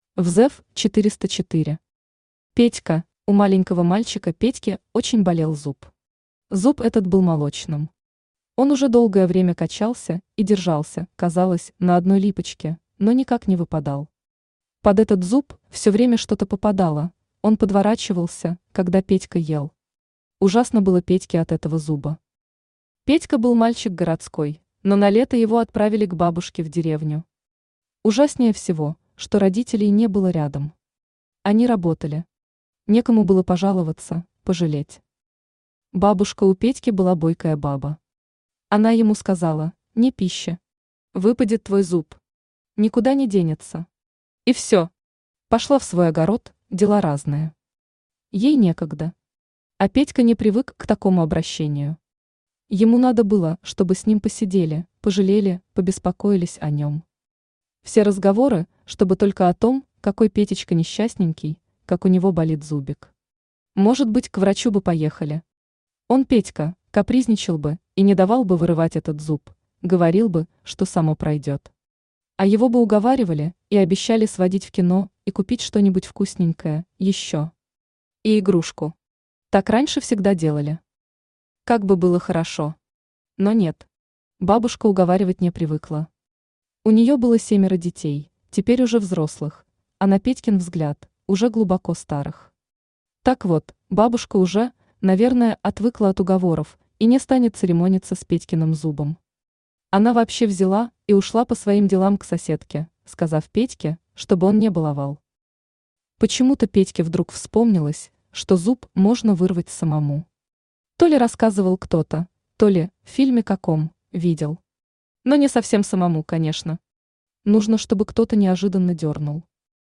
Аудиокнига Петька | Библиотека аудиокниг
Читает аудиокнигу Авточтец ЛитРес.